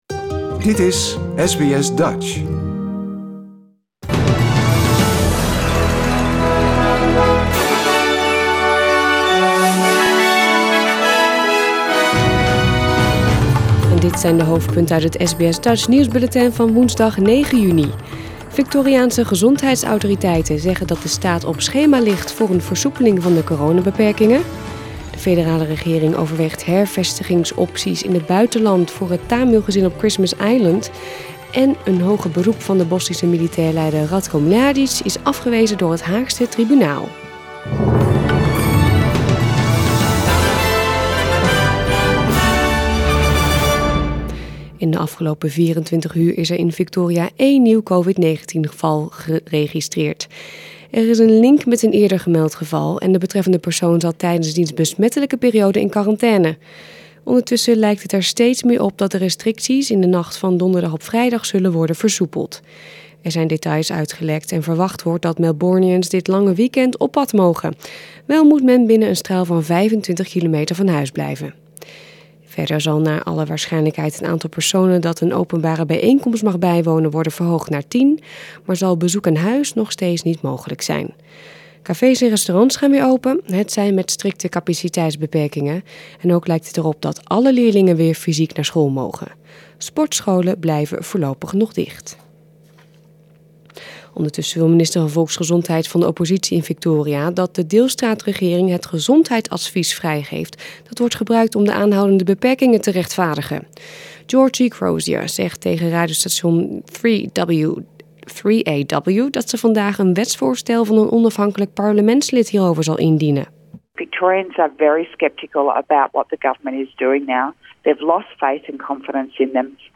Nederlands/Australisch SBS Dutch nieuwsbulletin van woensdag 9 juni 2021